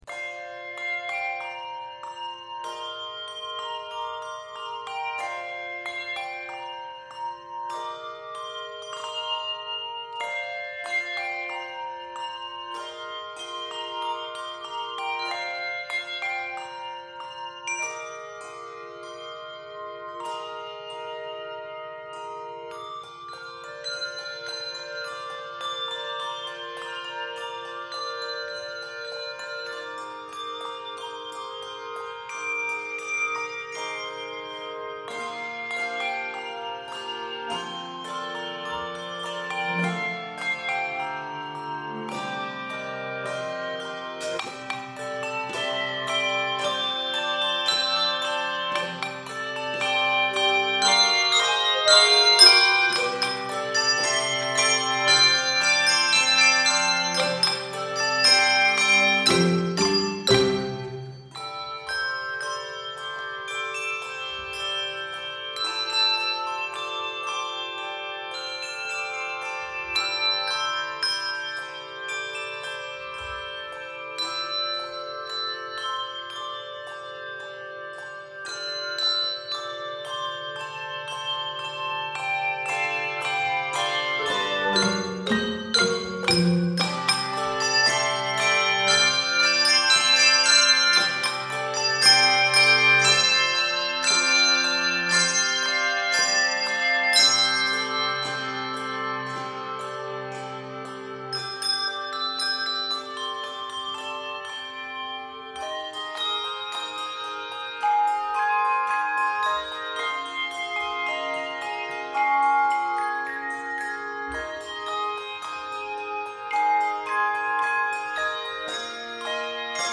Keys of Bb Major, C Major and Ab Major.